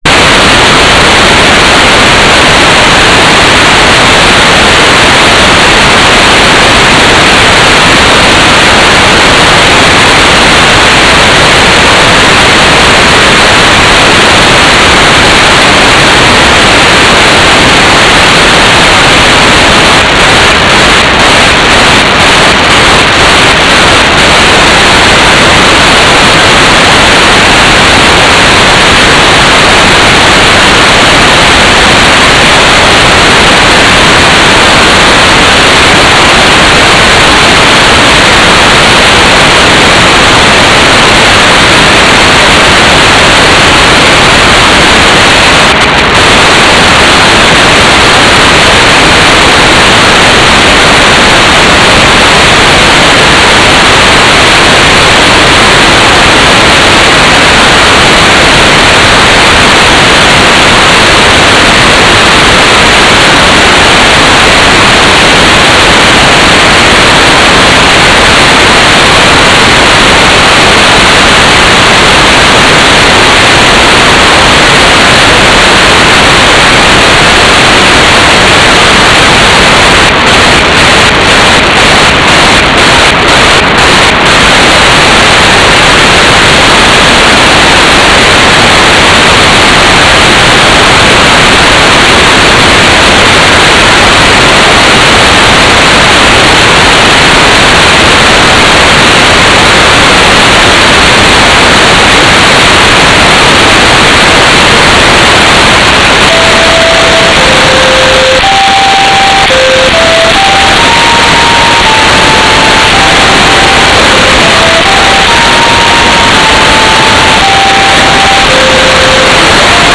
"transmitter_description": "Mode U - GFSK4k8 - AX.25 - Telemetry",